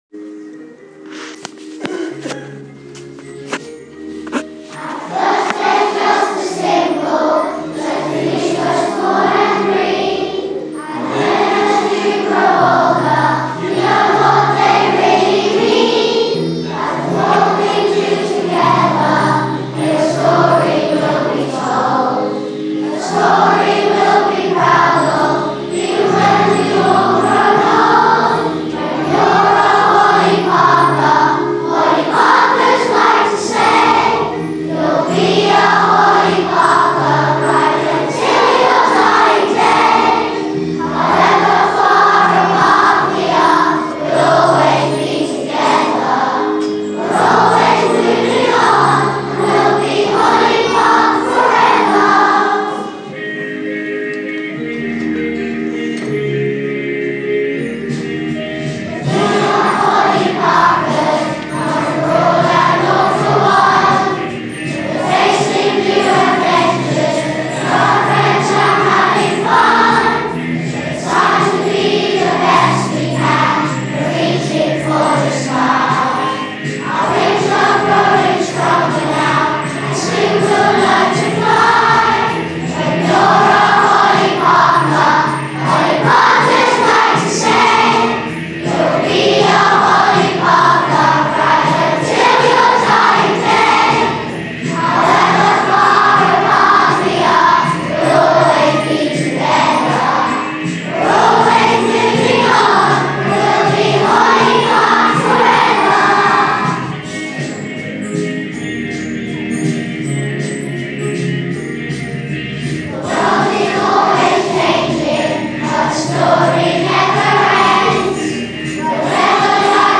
the whole school singing